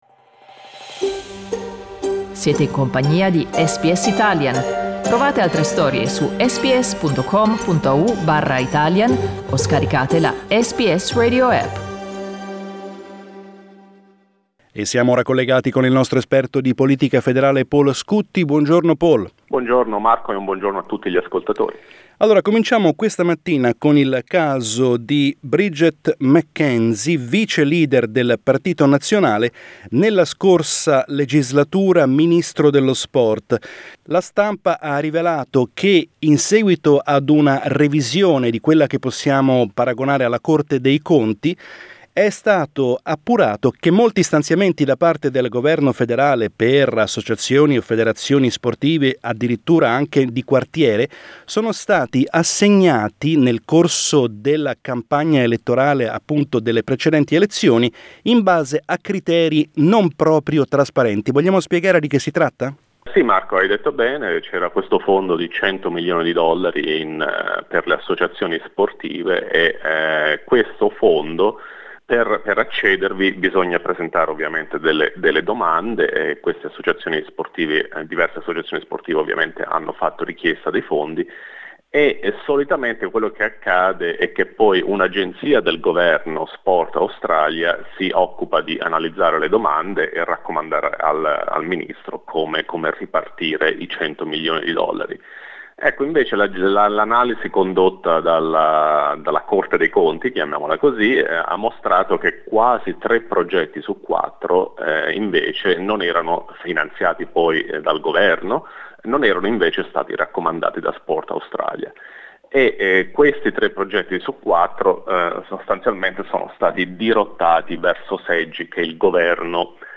Our analysis with federal politics correspondent